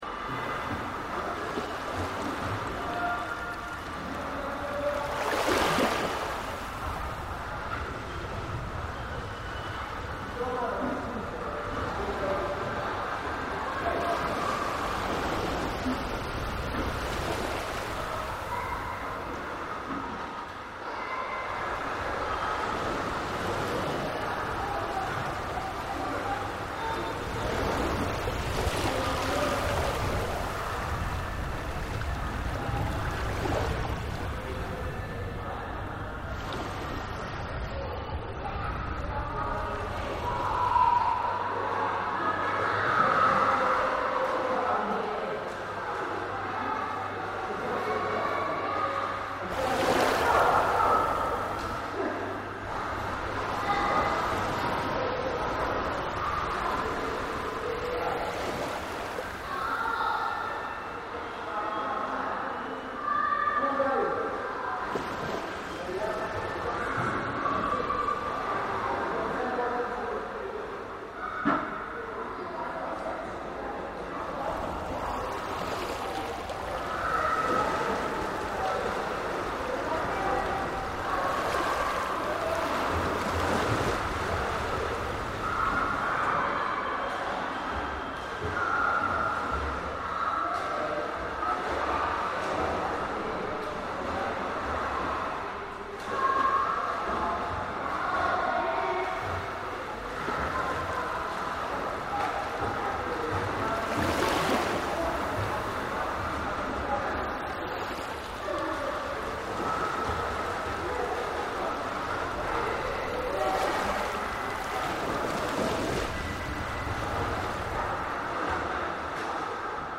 Listening through the bottom locker doors there appears aurally to be a large swimming pool space.
Stereo version of The Cally Pool and Gym audio recording here